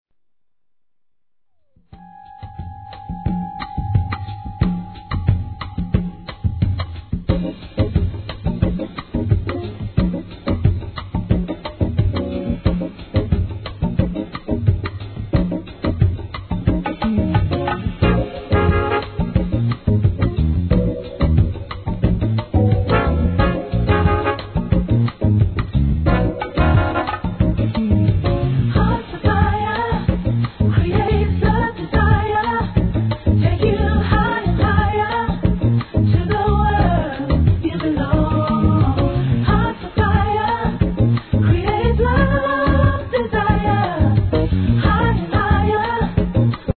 1. HIP HOP/R&B
得意のムーディーなミディアムでグッと来るヴォーカルを聴かせます!!